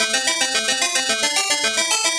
Index of /musicradar/8-bit-bonanza-samples/FM Arp Loops
CS_FMArp C_110-A.wav